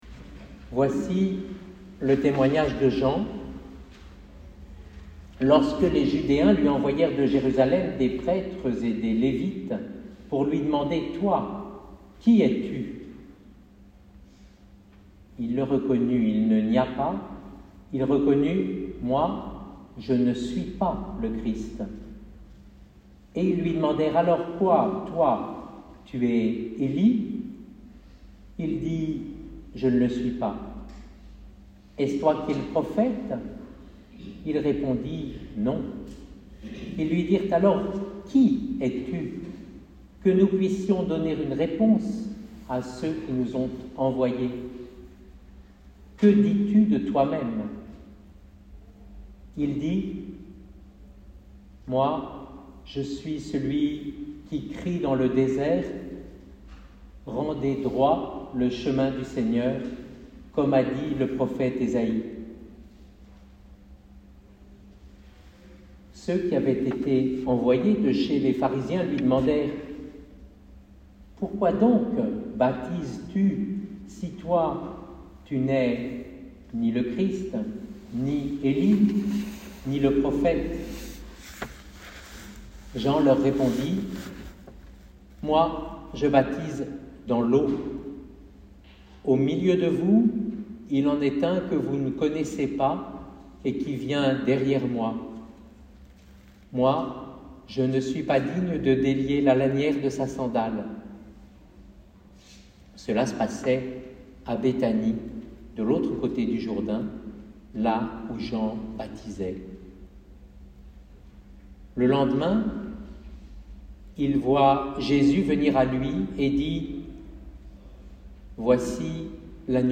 Lecture , prédication et orgue.mp3 (31.62 Mo)